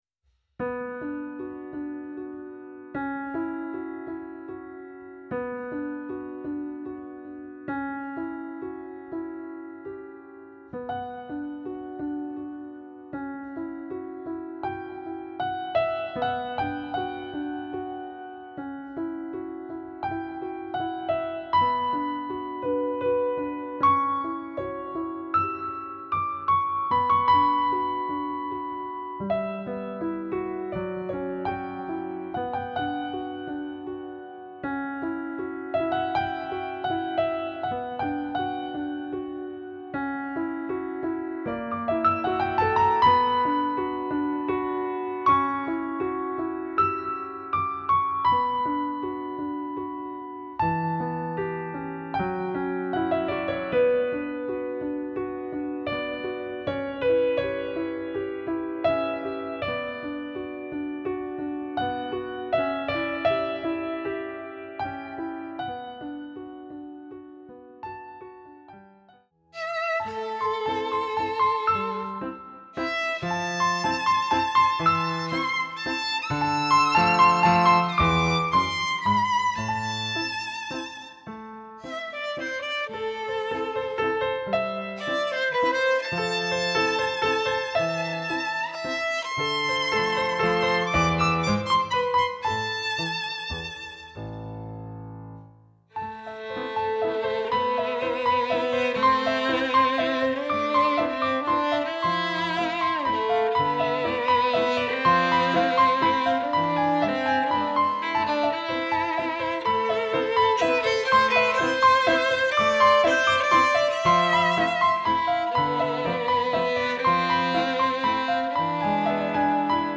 Akkordeon
Violine/Gesang
Percussion/Xylophon
Kontrabass
Audio-Mix